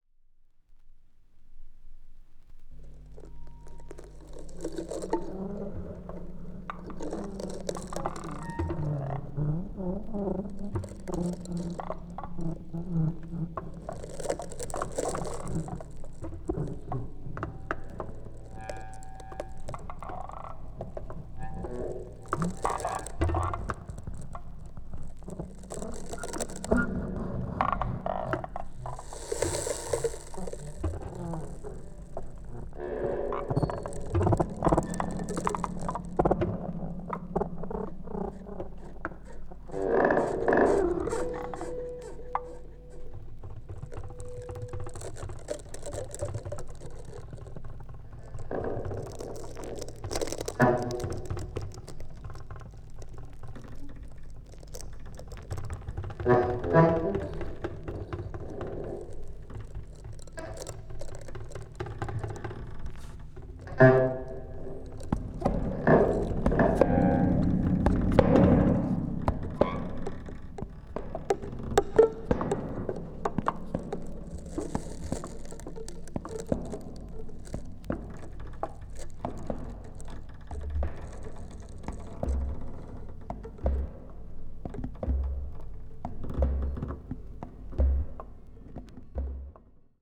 20th century   contemporary   electronic   experimental